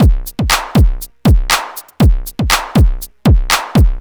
TSNRG2 Breakbeat 016.wav